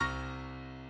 Mahler called the climax of the movement, a blistering Bm/C chord in triple-forte which occurs near the end, sometimes a "cry of despair", and sometimes a "death shriek".